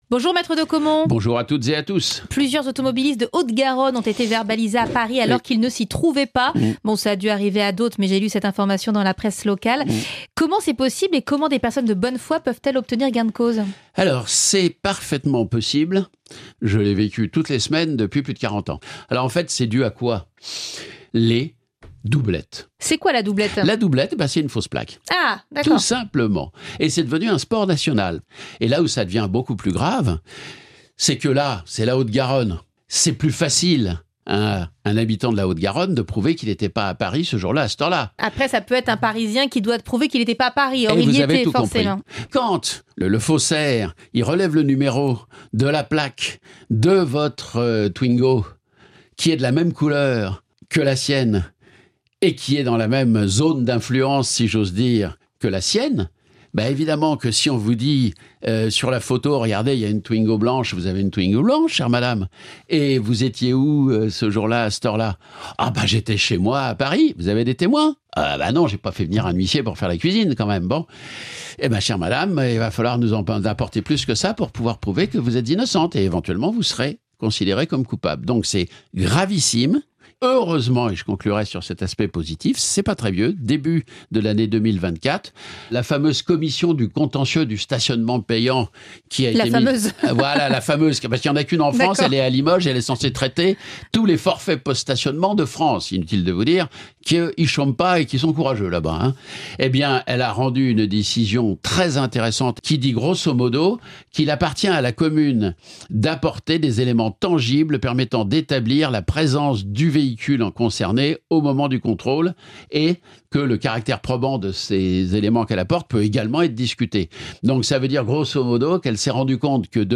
avocat spécialisé dans la défense des automobilistes.